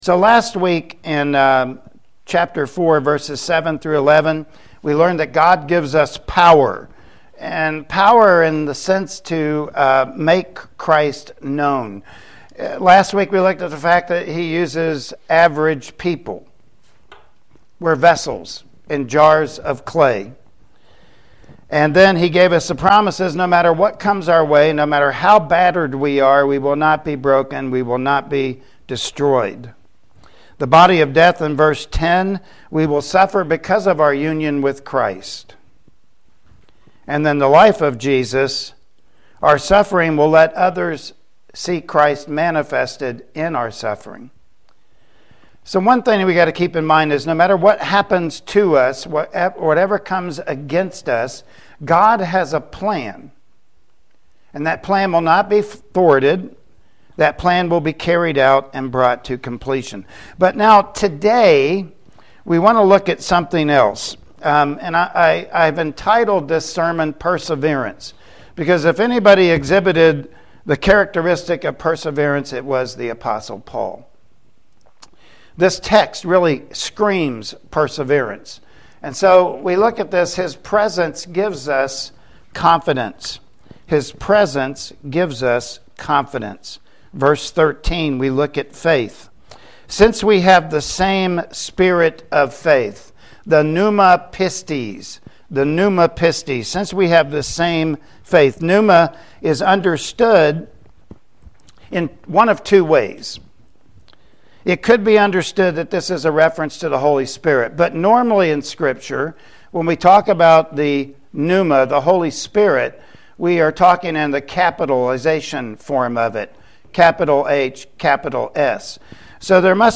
2 Corinthians 4:13-15 Service Type: Sunday Morning Worship Service Topics: Staying the Course